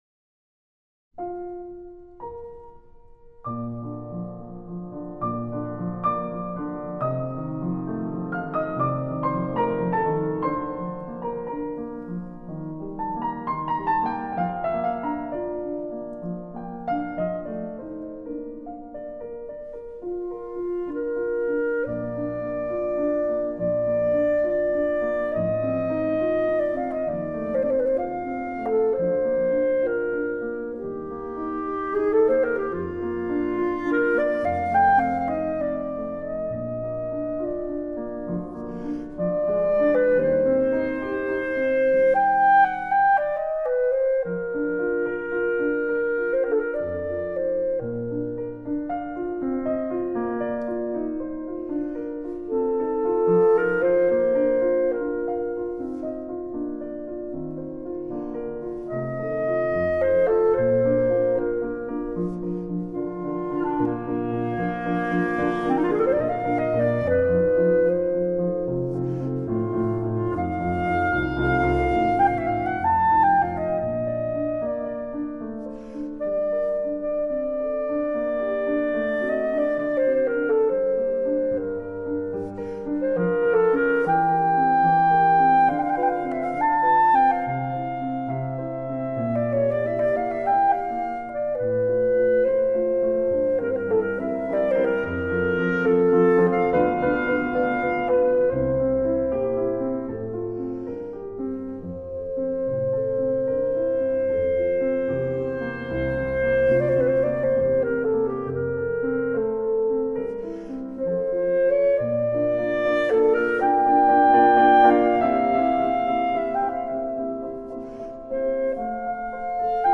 Per clarinetto e pianoforte